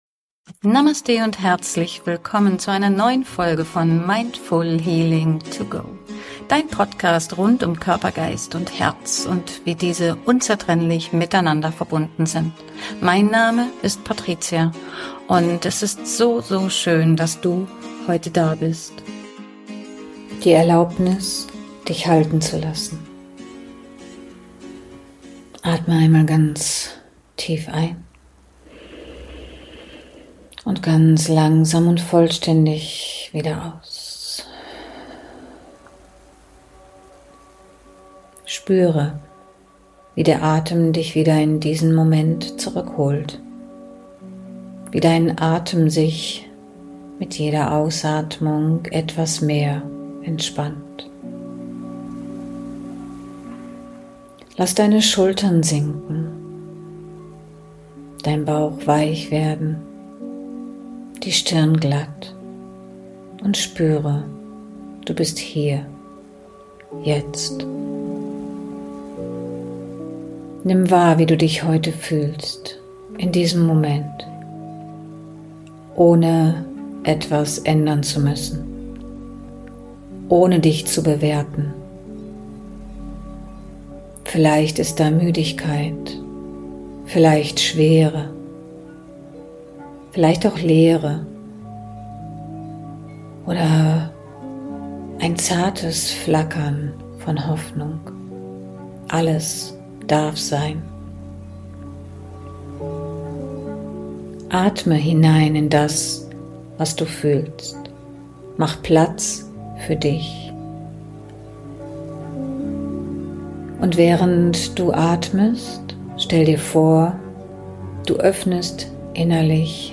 Meditation - Die Erlaubnis dich halten zu lassen